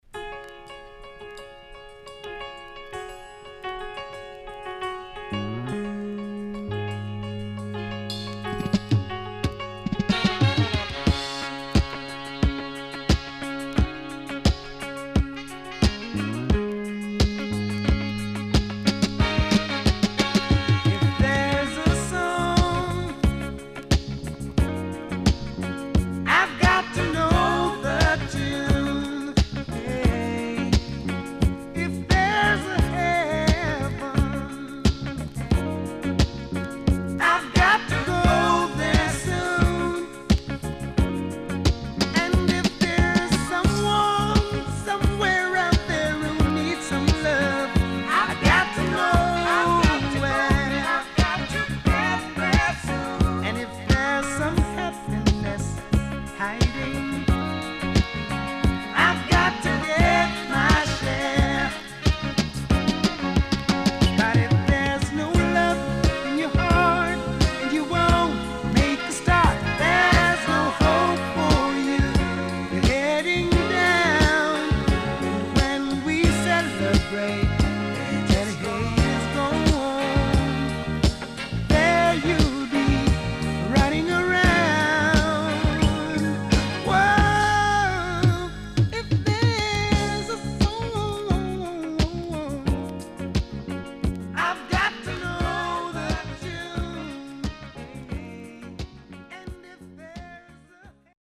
SIDE A:少しノイズあり、A-3傷によるパチノイズ入ります。